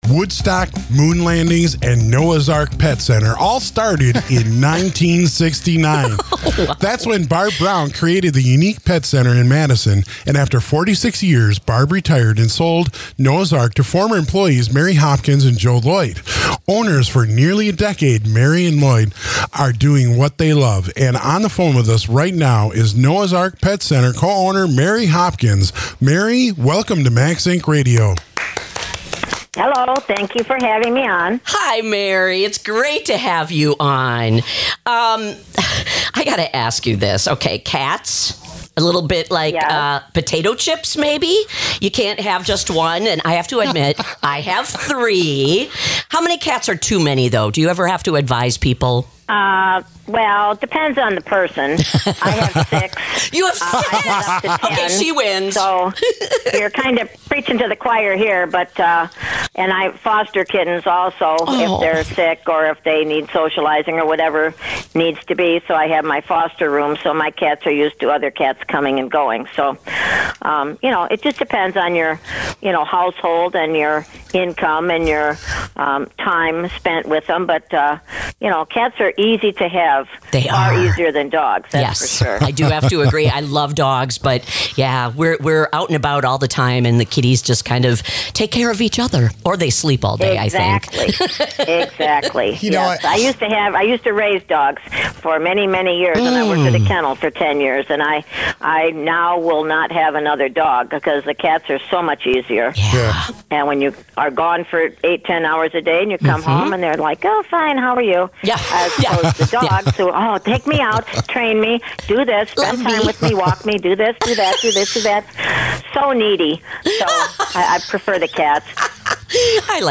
phones in to Max Ink Radio to talk kitten therapy, good pet food and more!